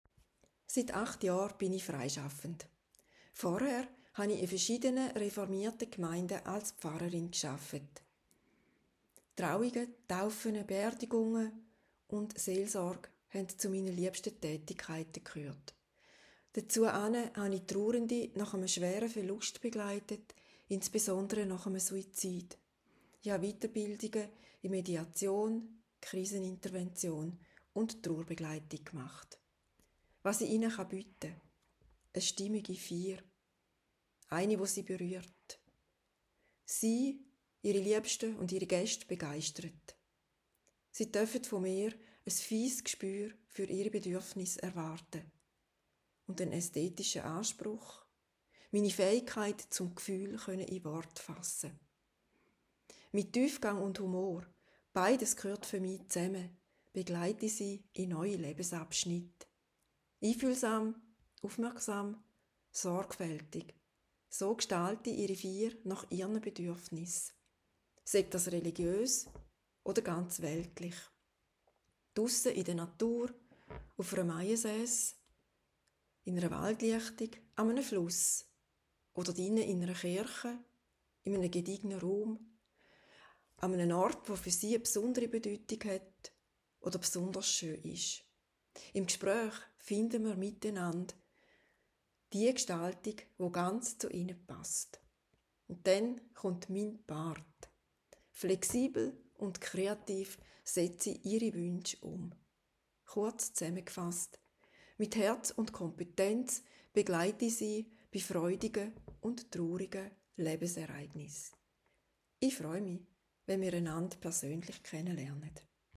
Stimmprobe-05-Favorit.m4a